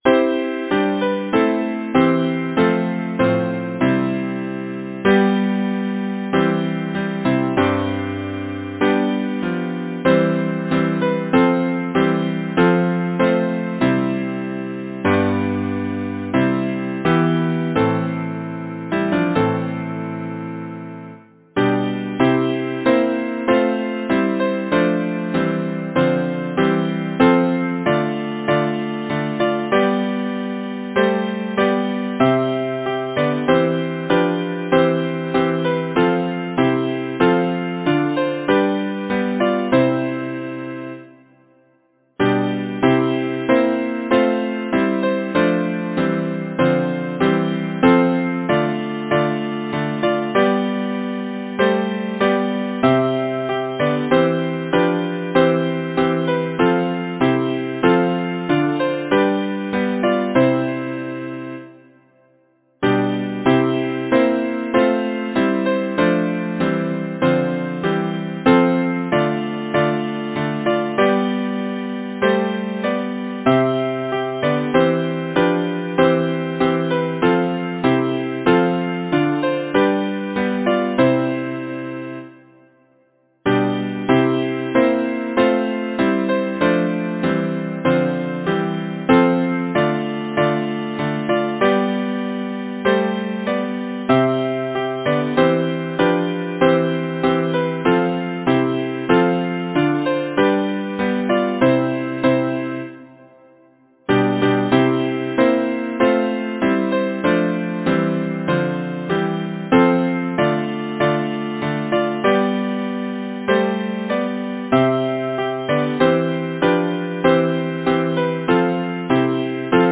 Title: Bonnie ran the burnie doon Composer: Henry A. Lambeth Lyricist: Carolina Oliphant Number of voices: 4vv Voicing: SATB Genre: Secular, Partsong
Language: Lowland Scots Instruments: A cappella